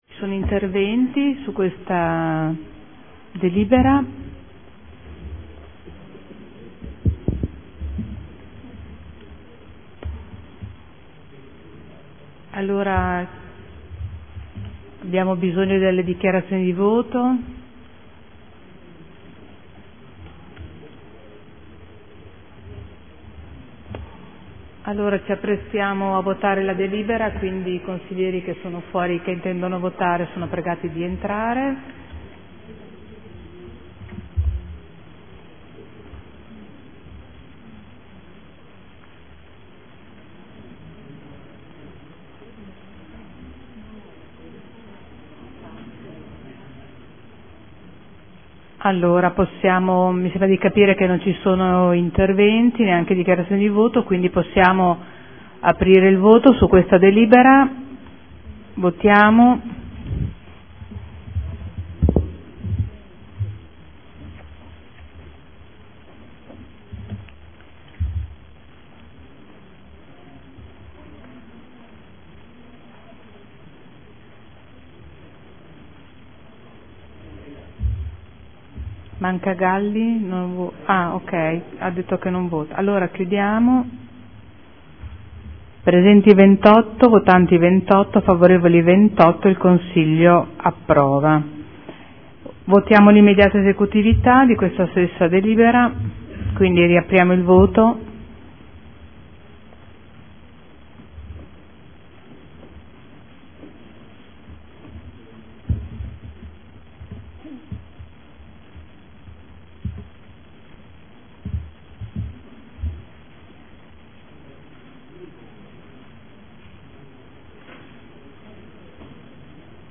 Seduta del 20/03/2014 Mette ai voti. Risorse finanziarie ai gruppi consiliari anno 2014 – Primo stralcio. Approvata all'unanimità.